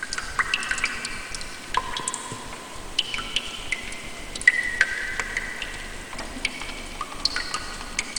mb-assets/water-drips-echo-2.ogg at acf95b69b28fac2803af3af48e35a7a53e154d59
water-drips-echo-2.ogg